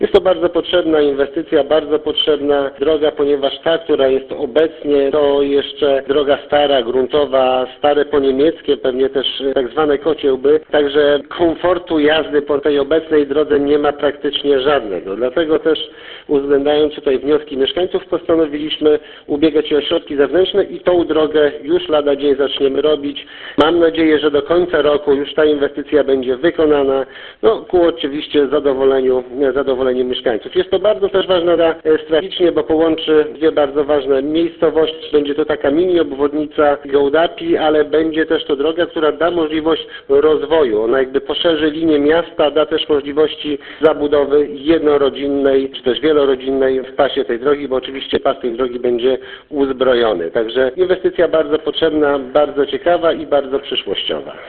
– To ważna inwestycja- mówi burmistrz Gołdapi Tomasz Luto.